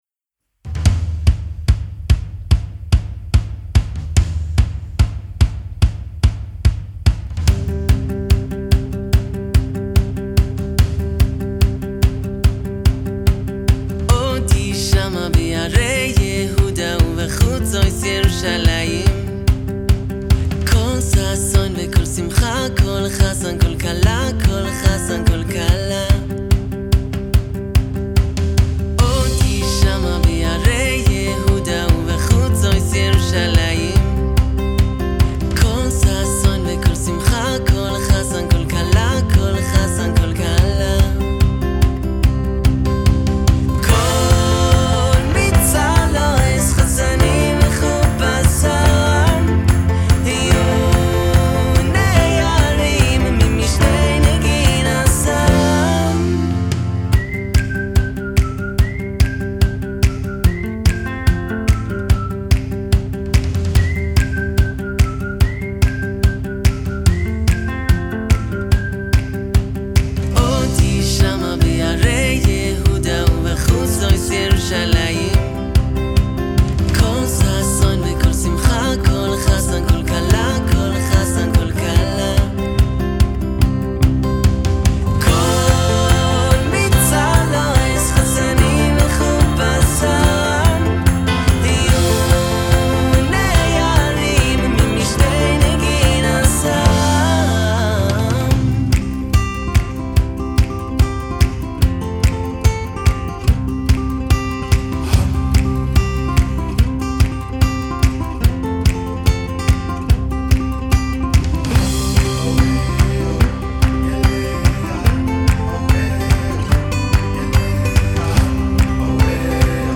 הלהקה מייצרת מוזיקה יהודית עם השפעות בינלאומיות.